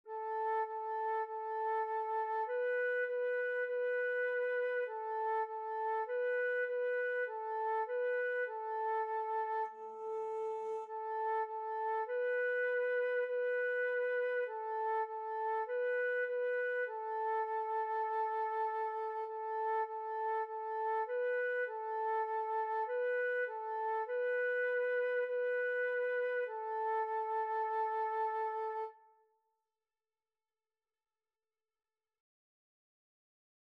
4/4 (View more 4/4 Music)
A5-B5
Flute  (View more Beginners Flute Music)
Classical (View more Classical Flute Music)